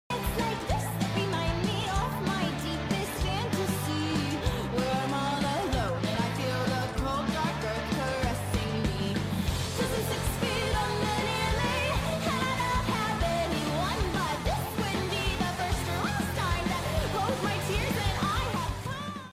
Shivers....gulps... WHAT DO YPU MEAN sound effects free download